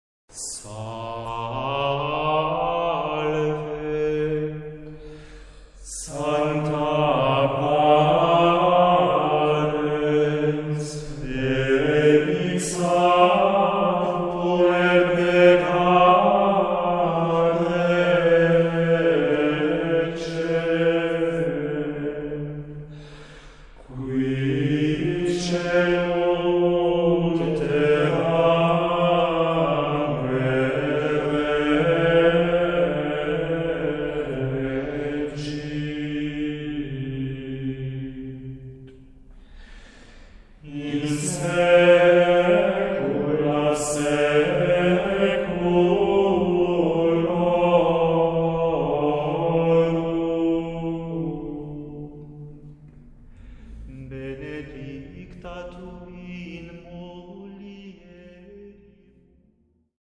* Gravado na Igreja do Carmo, Beja, Portugal em 2004